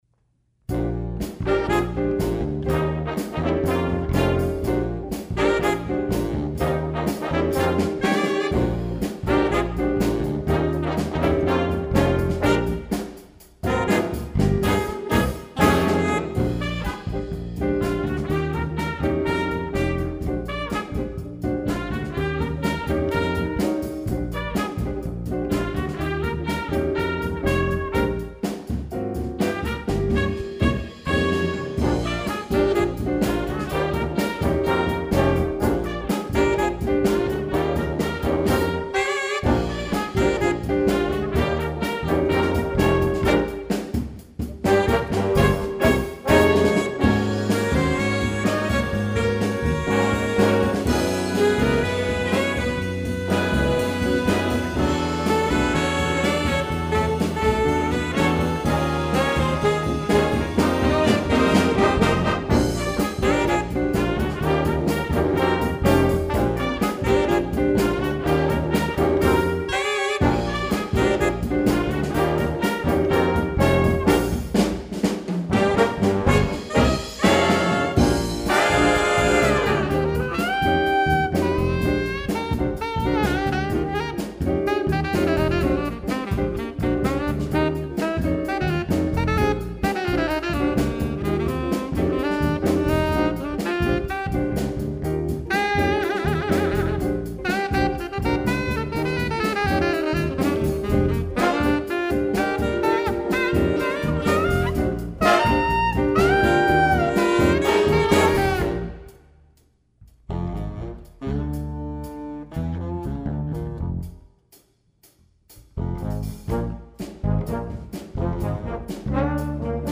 Rhythm parts are fully notated.
Optional drum solo.